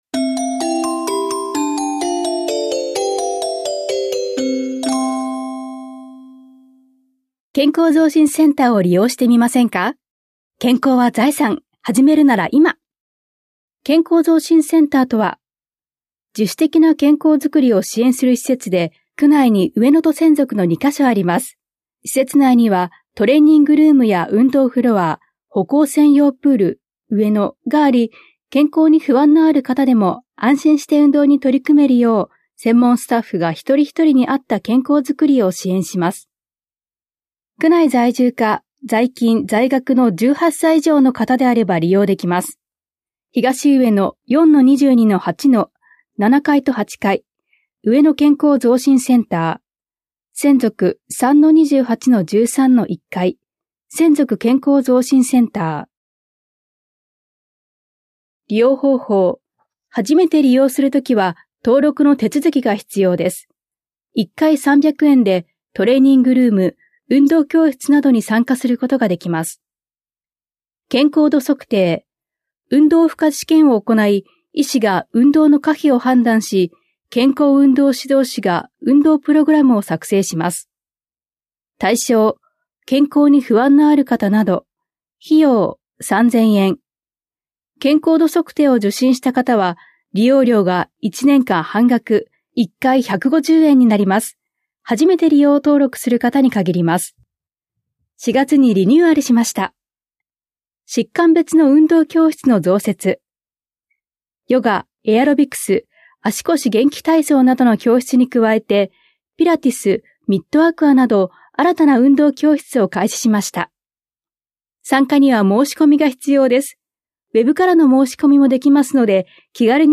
広報「たいとう」令和7年5月5日号の音声読み上げデータです。